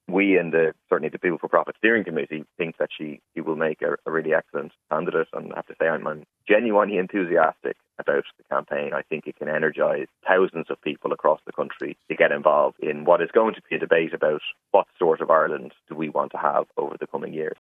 People Before Profit TD, Paul Murphy, says Catherine Connolly would make the perfect president: